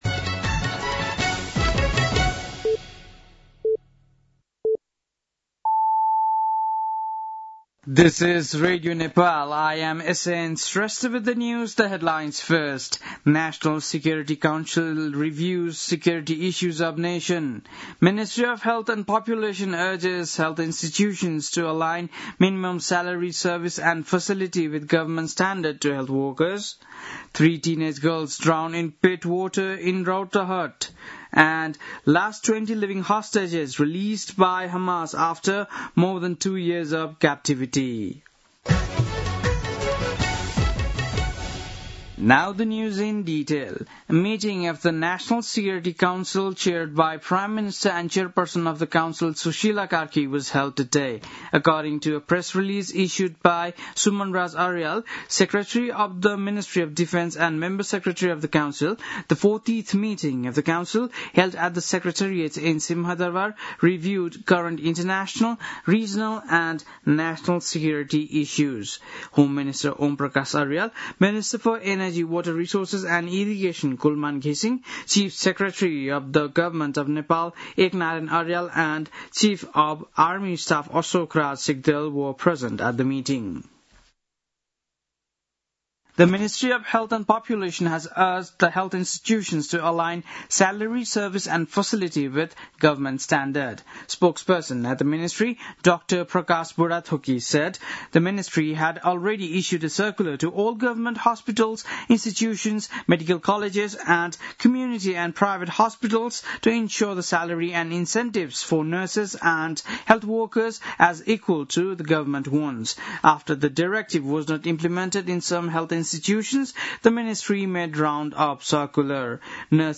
बेलुकी ८ बजेको अङ्ग्रेजी समाचार : २७ असोज , २०८२
8-pm-english-news-6-27.mp3